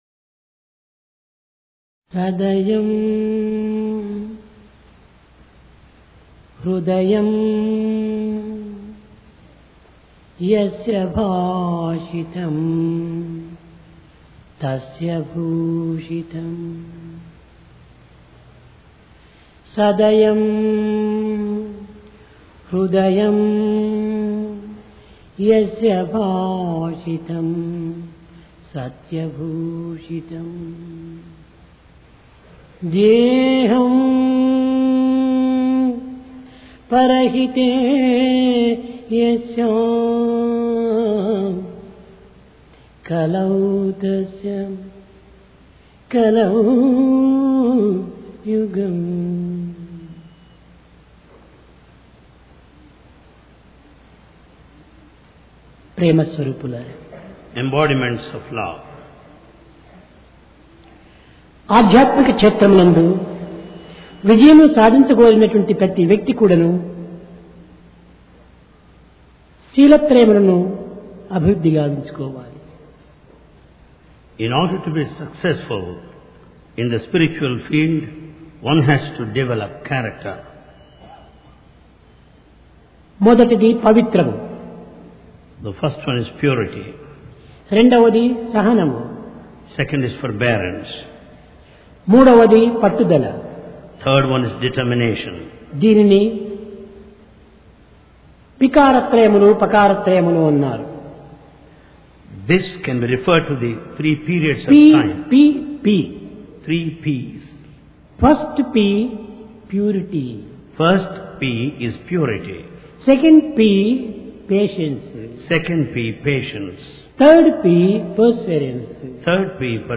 Sai Darshan Home Date: 24 Jun 1996 Occasion: Divine Discourse Place: Prashanti Nilayam Sense Control For Spiritual Progress To be successful in the spiritual field, character is essential.